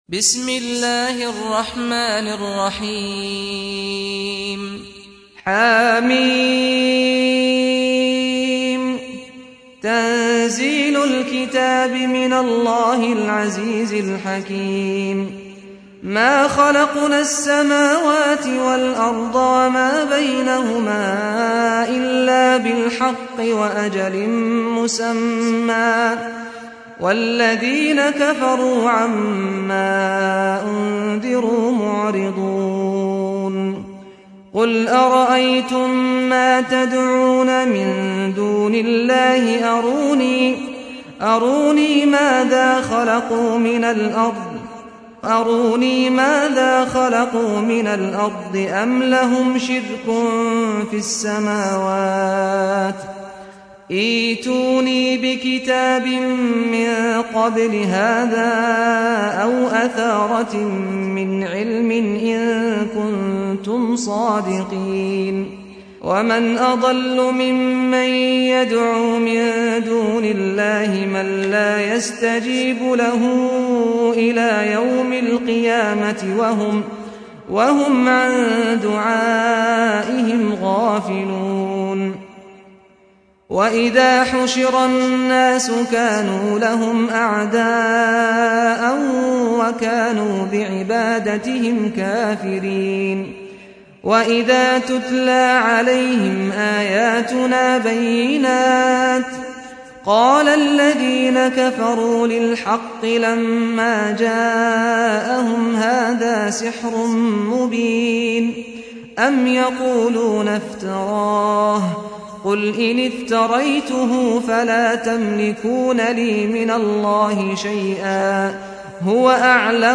سُورَةُ الأَحۡقَافِ بصوت الشيخ سعد الغامدي